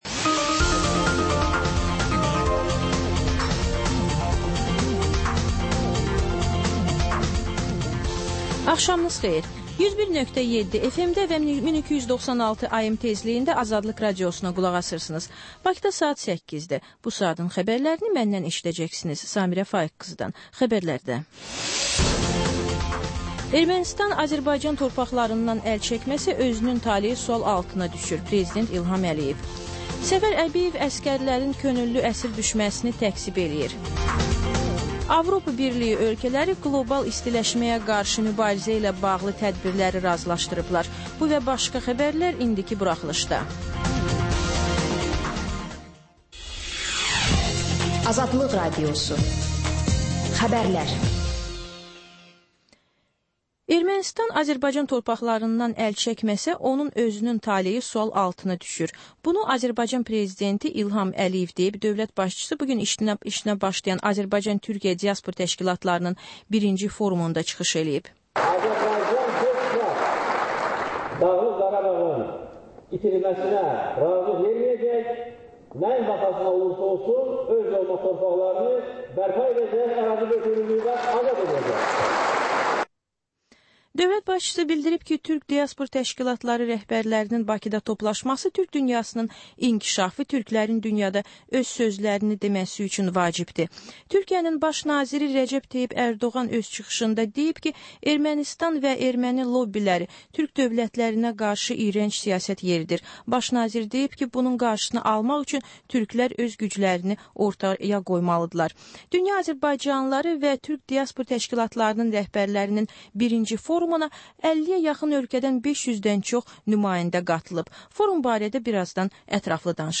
Xəbər, reportaj, müsahibə. Sonra: Günün Söhbəti: Aktual mövzu barədə canlı dəyirmi masa söhbəti.